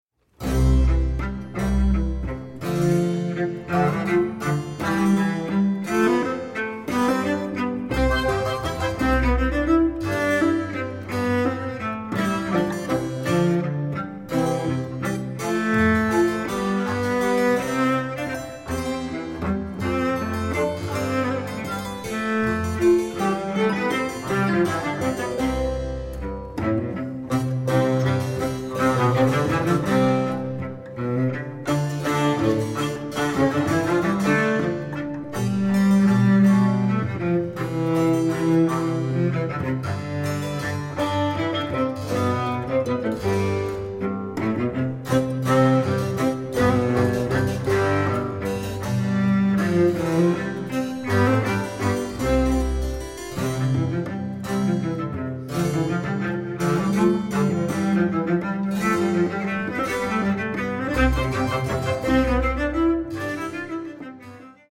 • Genres: Early Music, Opera